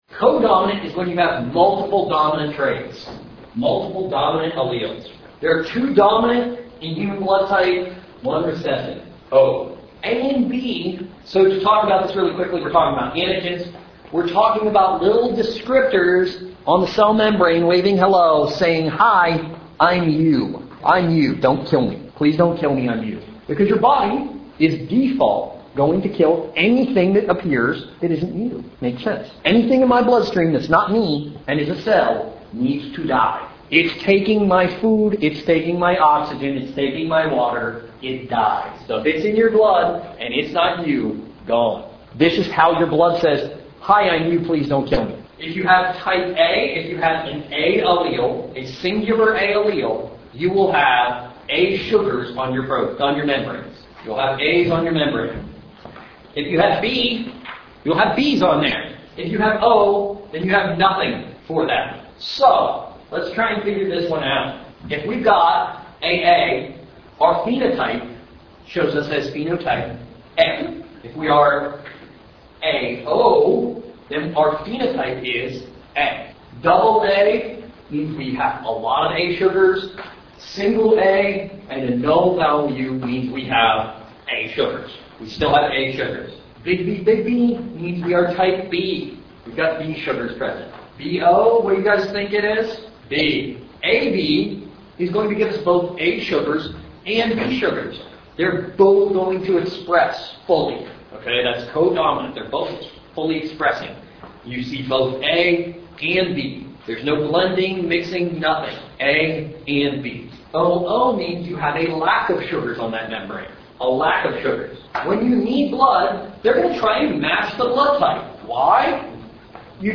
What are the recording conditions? Blood - audio from class lecture